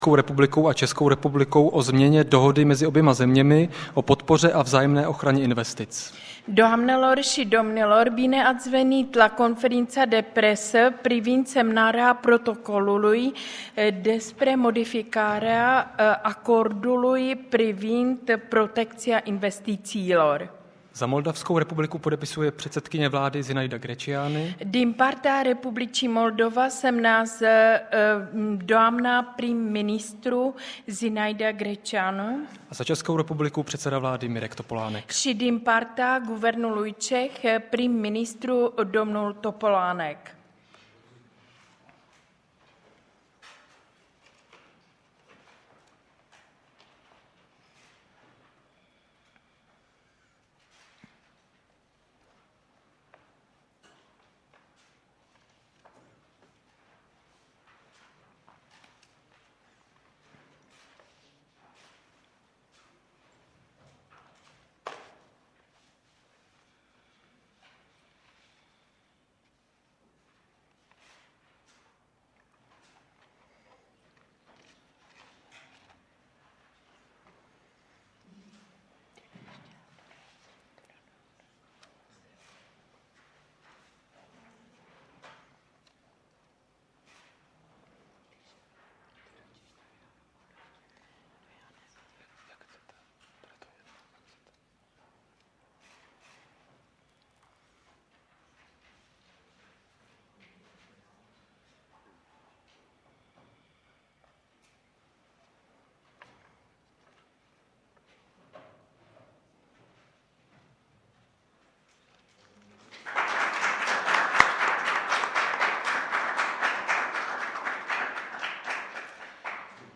Tisková konference premiéra M. Topolánka a předsedkyně vlády Moldavské republiky Z. Greceanii 2. 9. 2008